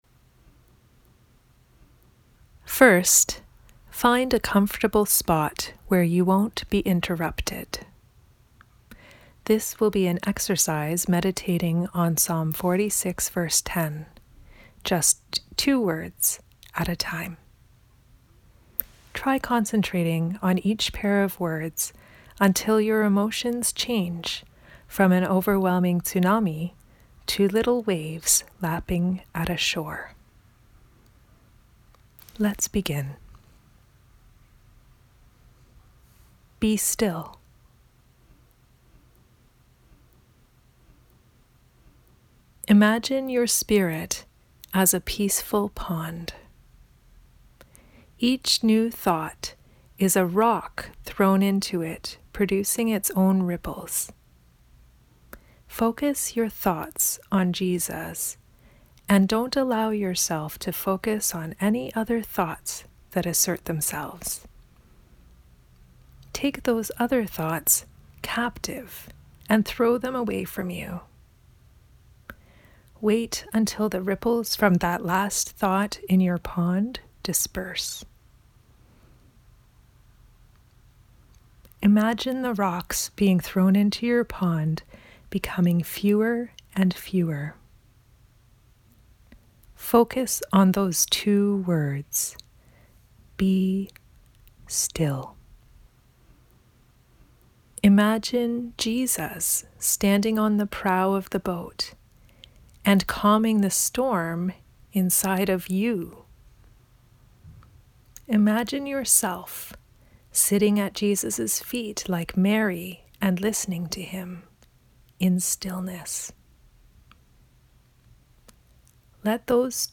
Below, I’ve included a 10-minute meditation exercise. I find audio guided meditation to be the most helpful, so that’s what I’ve done for you here.
Meditation.mp3